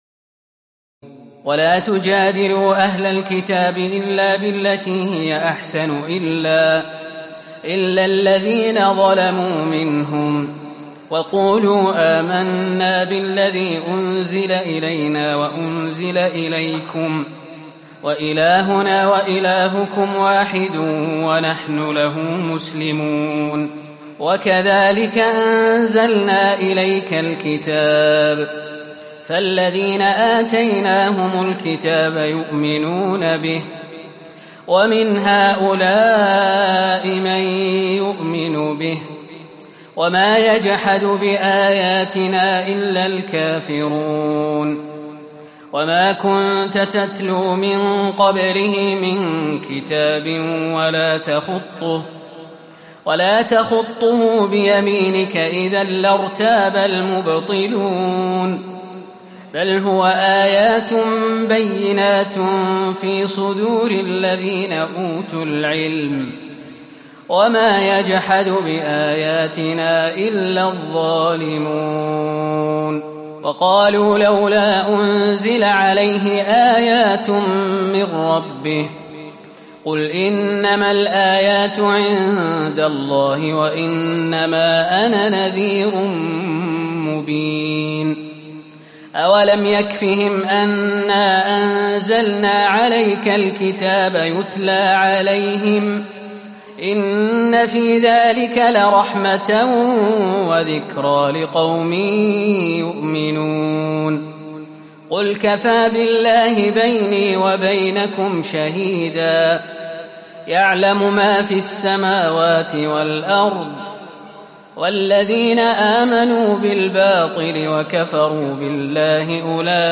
تراويح الليلة العشرون رمضان 1438هـ من سور العنكبوت (46-69) و الروم و لقمان (1-11) Taraweeh 20 st night Ramadan 1438H from Surah Al-Ankaboot and Ar-Room and Luqman > تراويح الحرم النبوي عام 1438 🕌 > التراويح - تلاوات الحرمين